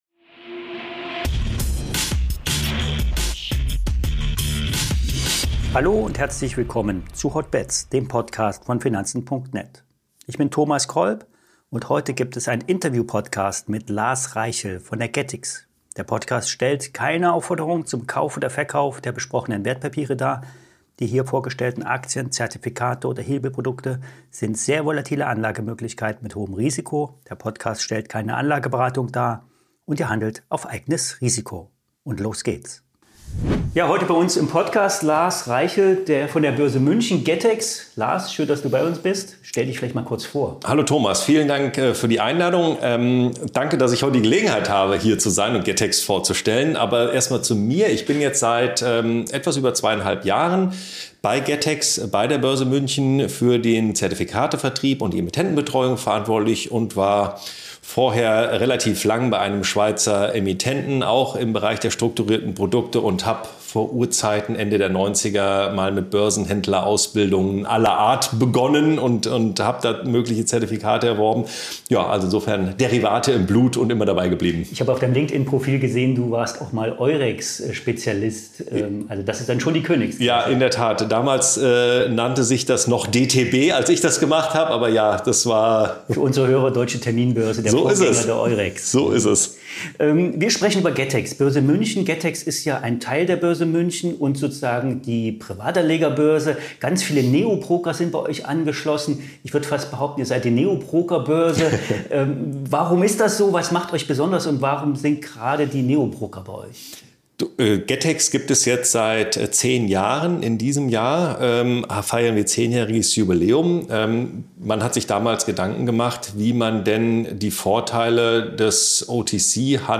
Sondersendung